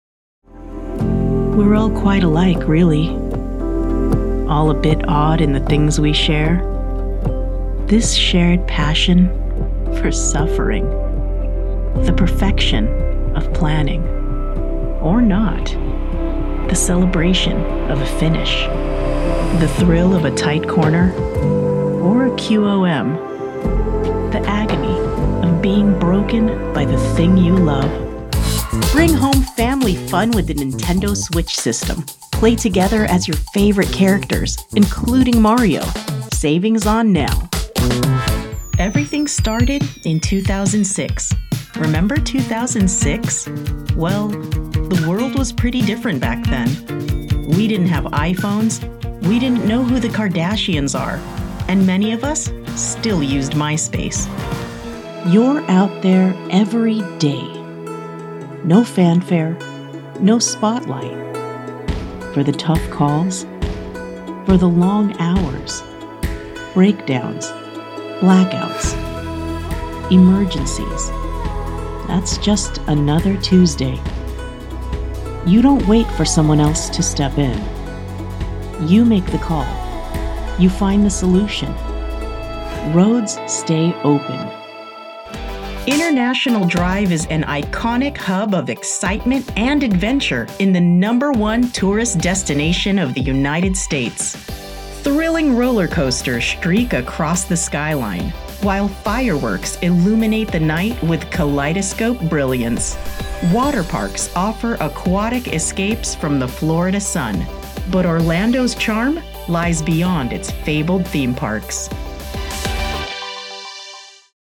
Voice Artist specializing in Empathic Trustworthy performances
Tagalog accent
Young Adult
Middle Aged
Commercial